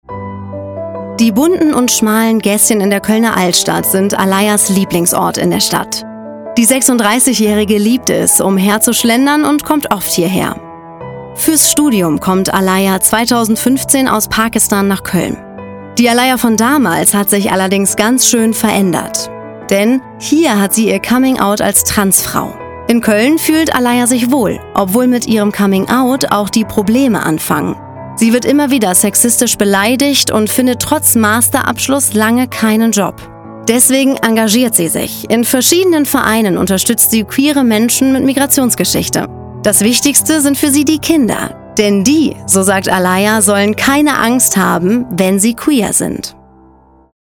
markant, dunkel, sonor, souverän
Mittel minus (25-45)
Doku, News (Nachrichten), Off